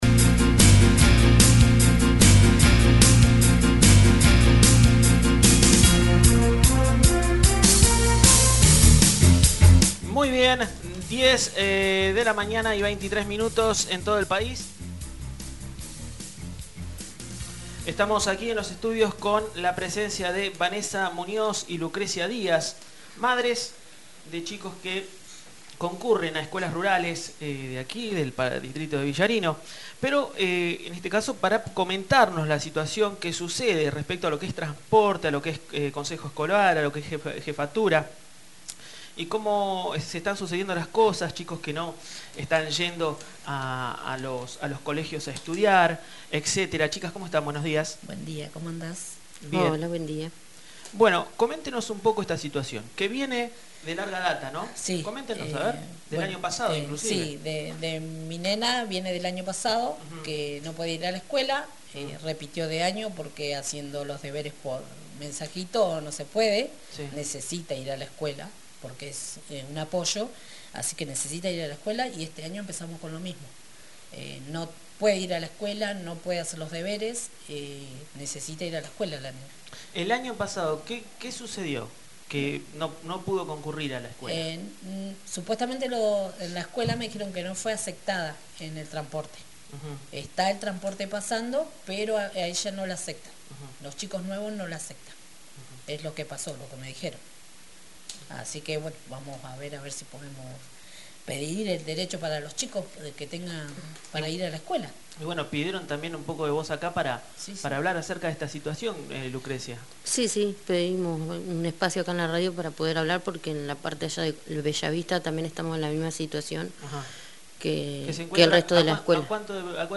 Dos madres de la Zona rural de Villarino hablan en nombre de todas las familias y chicos afectados por esta situación.